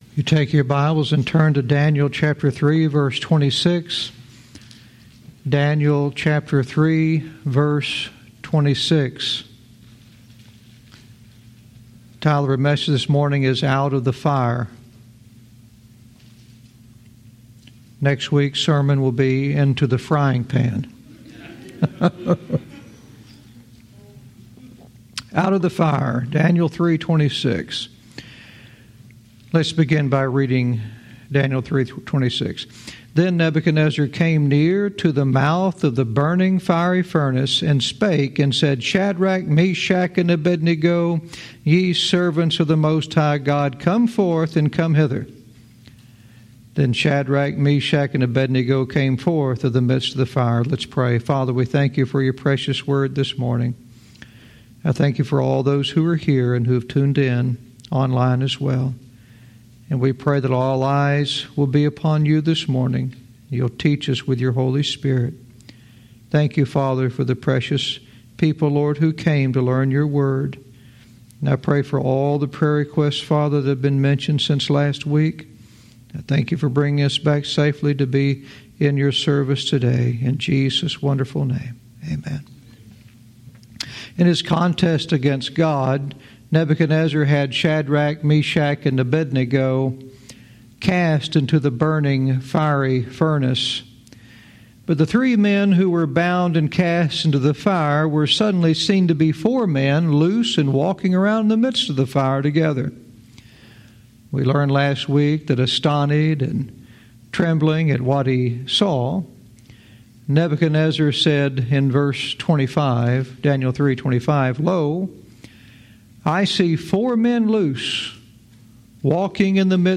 Verse by verse teaching - Daniel 3:26 "Out of the Fire"